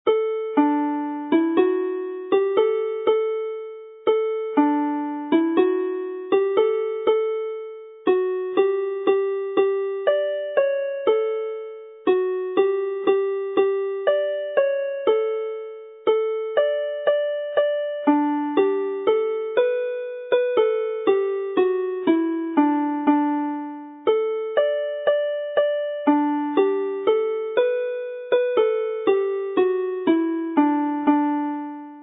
Triban syml yn D - alaw sylfaenol y gân
Basic Triplet in D with each line repeated